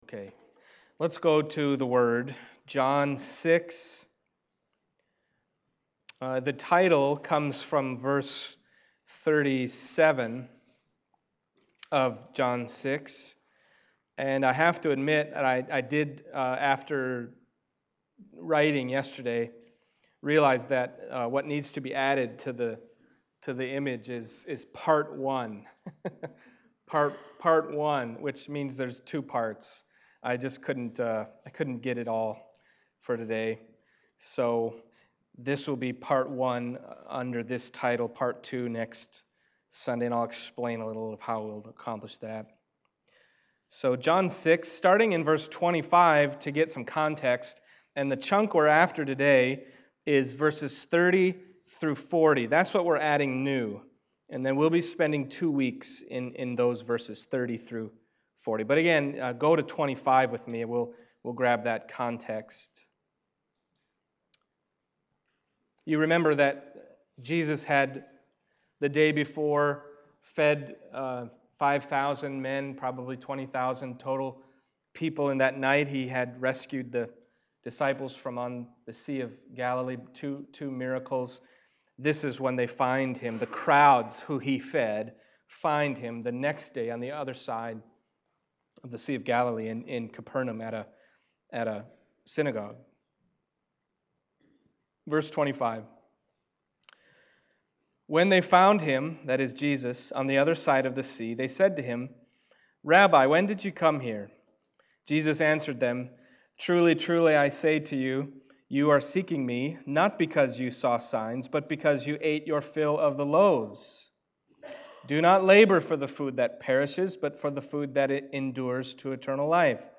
Passage: John 6:30-40 Service Type: Sunday Morning